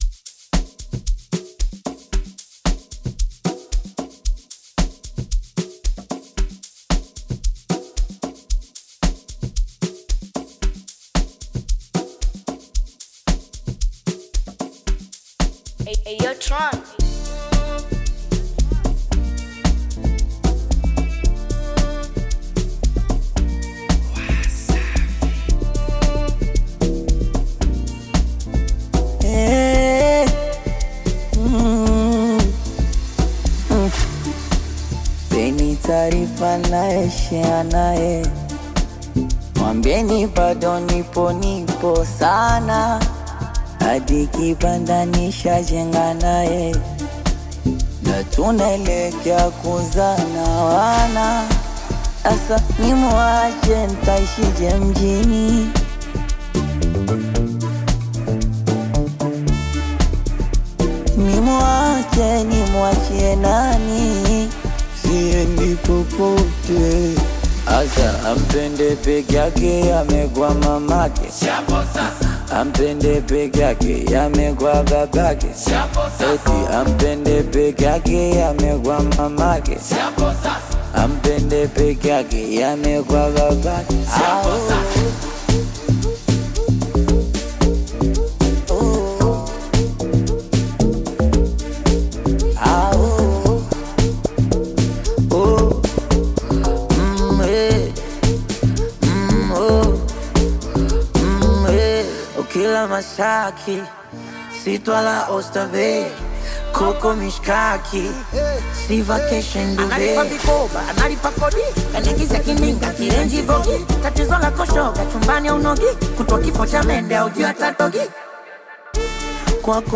” a melodious and captivating track.
soulful delivery
vibrant beats